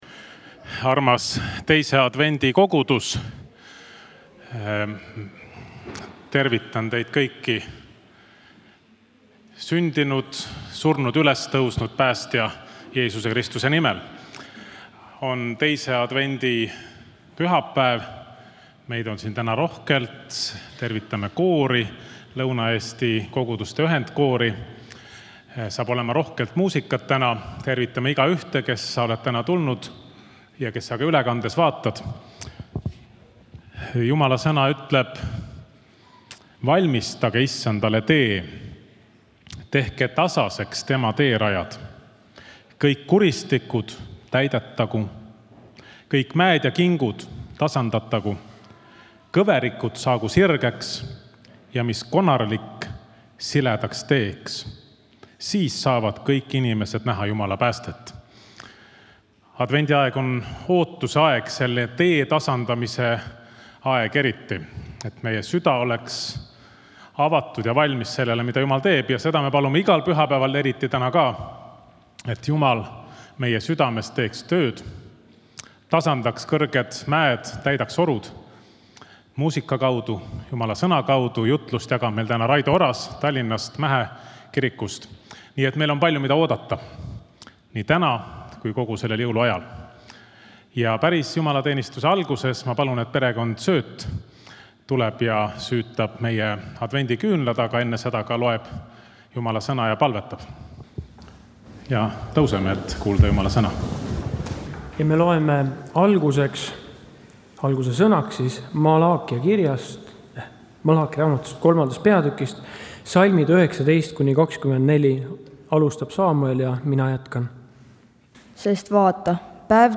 Kõik jutlused
Muusika: Lõuna-Eesti koguduste ühiskoor